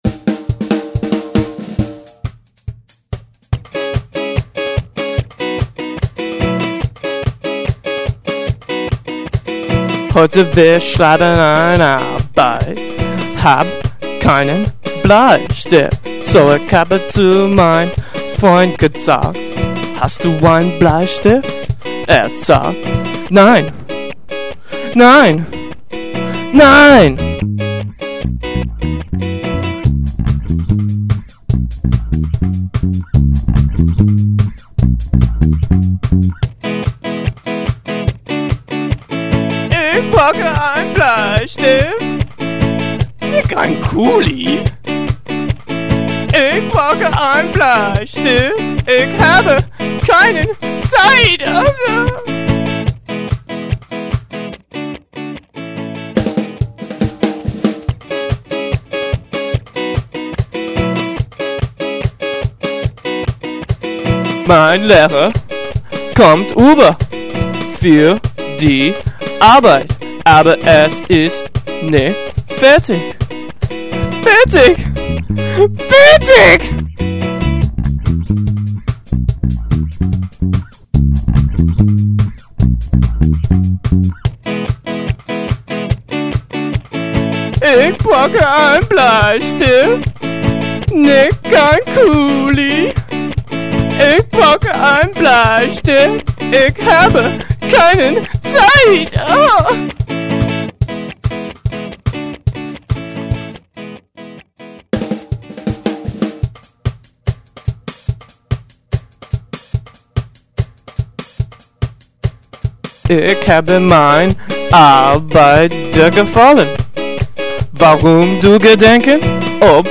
Drums
Guitar
Keyboard
Bass/German Rapper